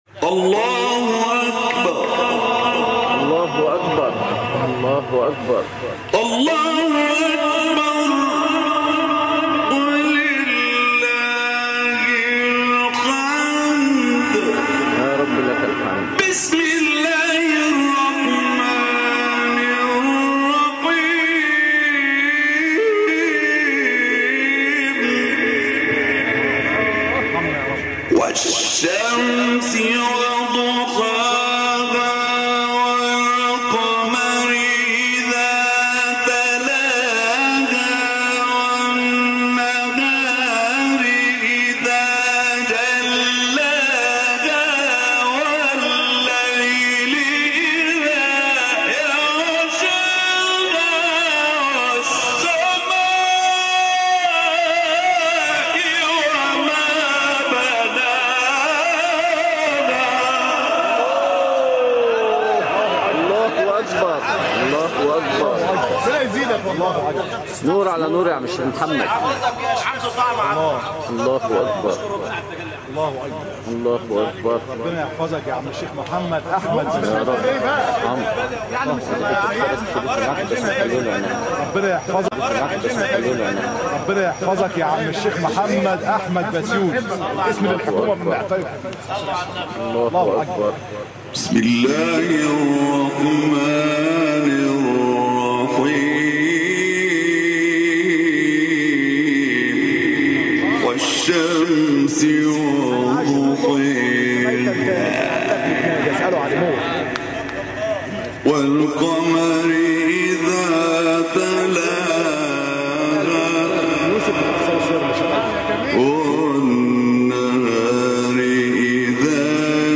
تلاوت سوره شمس